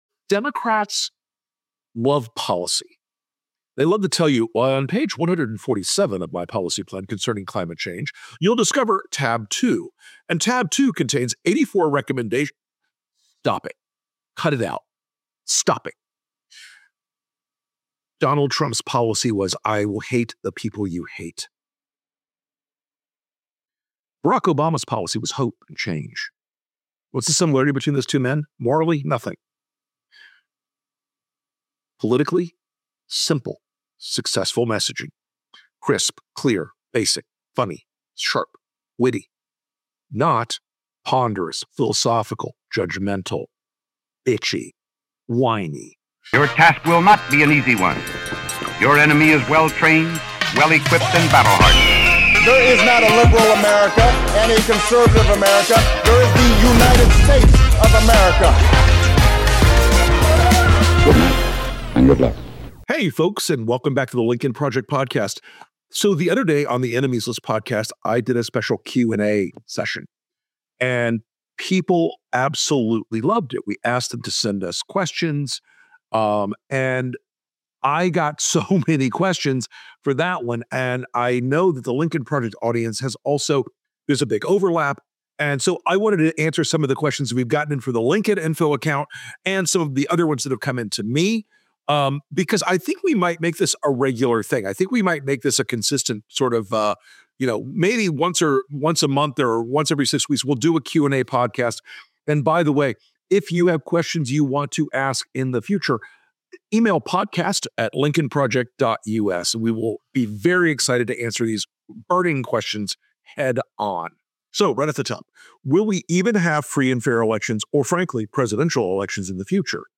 Debuting a new installment of the LP podcast, Rick reads and answers mail from our devoted LP audience.